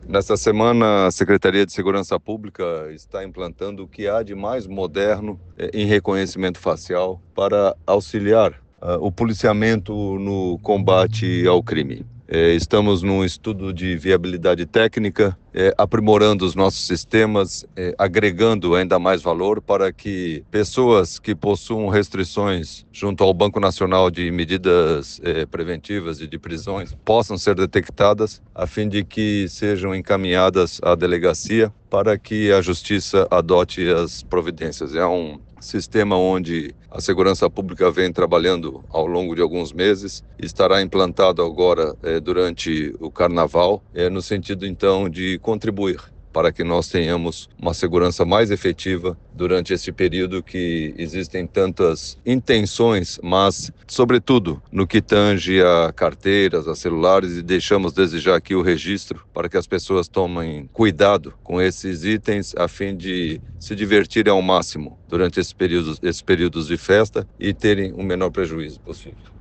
O secretário de Estado da Segurança Pública, coronel Flávio Graff, reafirma a intenção de ter um carnaval seguro e divertido: